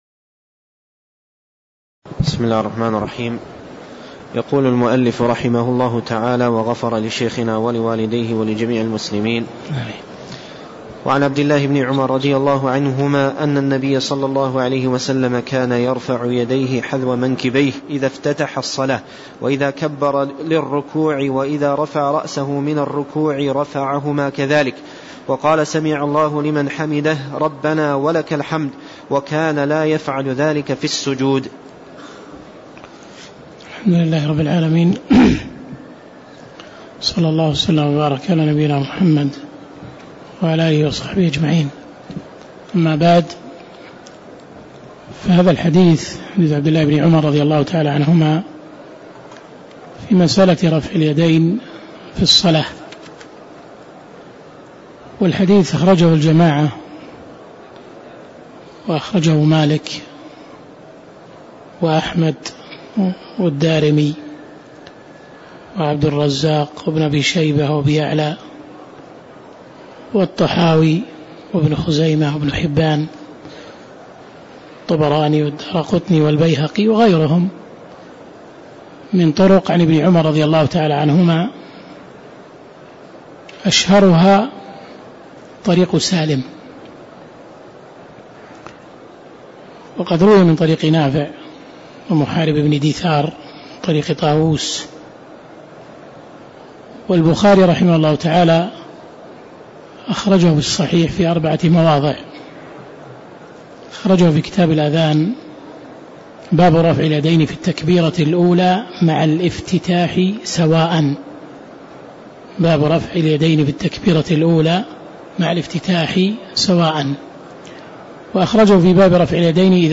تاريخ النشر ٧ شعبان ١٤٣٦ هـ المكان: المسجد النبوي الشيخ